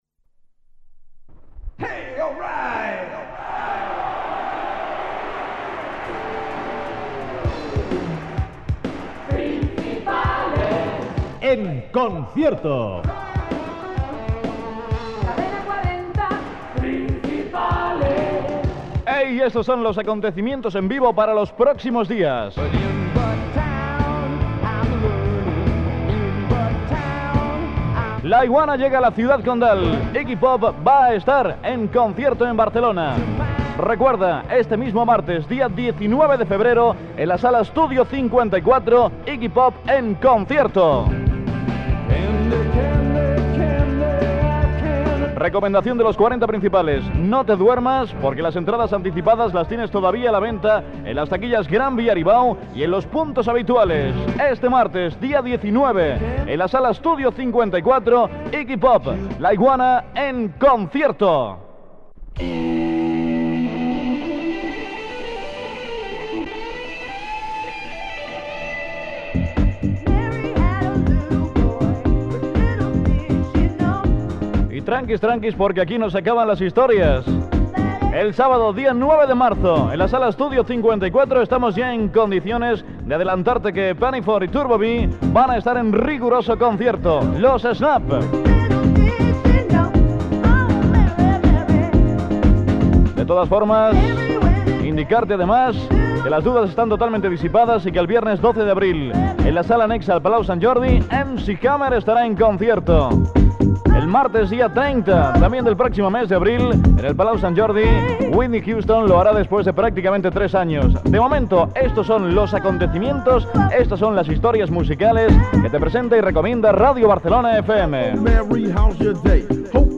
Ràdio Barcelona FM
Musical